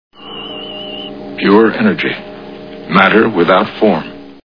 Star Trek TV Show Sound Bites